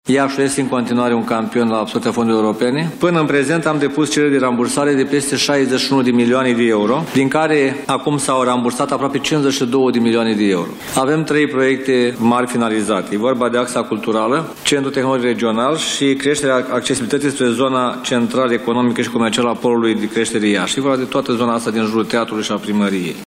Primarul Iașului, Gheorghe Nichita, a prezentat, astăzi, în cadrul unei conferințe de presă, stadiul proiectelor realizate, în municipiu, cu fonduri europene.